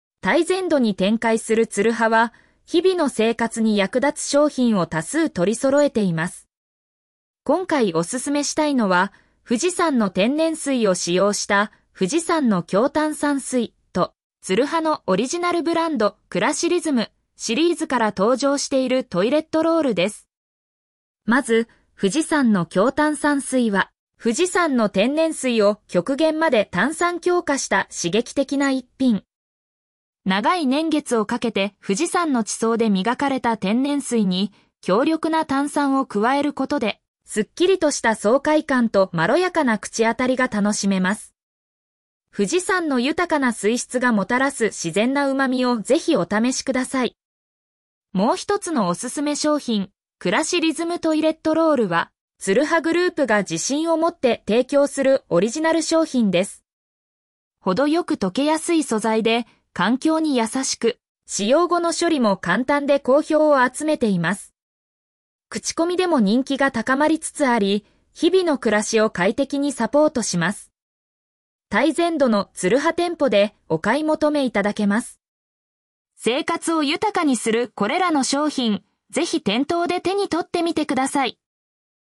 読み上げ